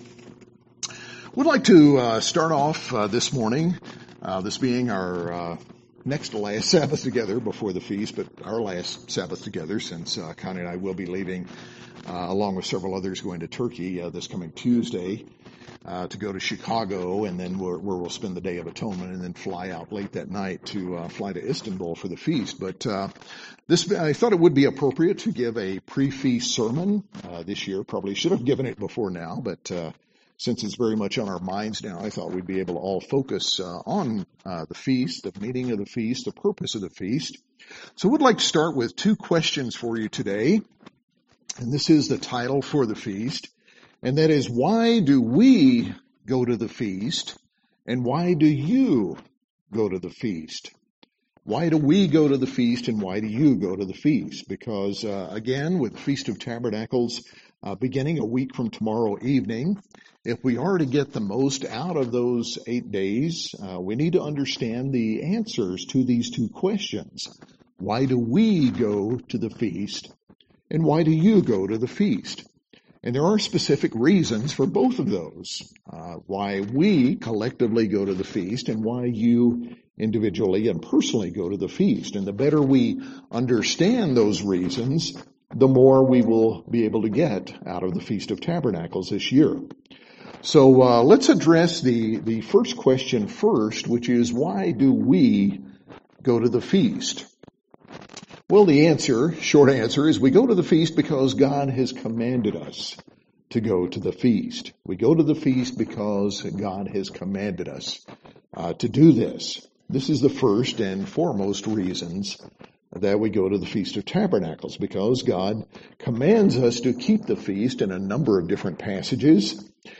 In this sermon, we look at seven key reasons from the Bible.